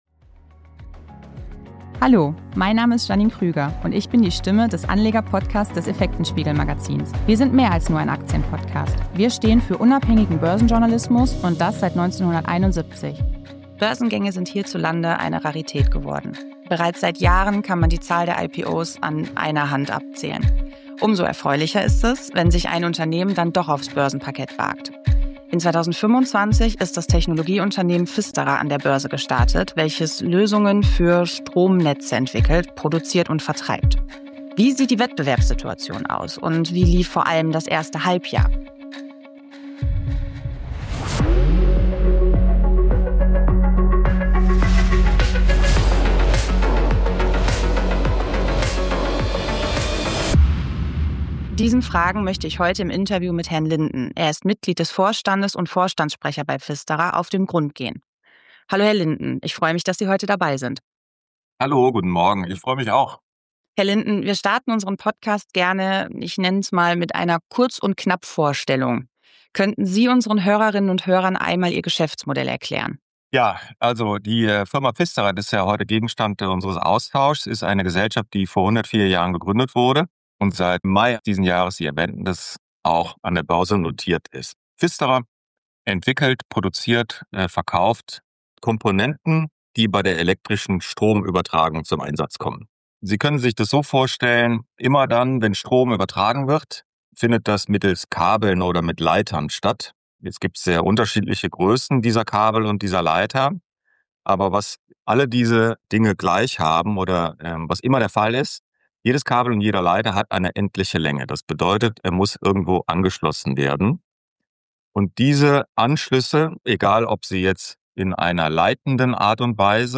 170. Pfisterer im Interview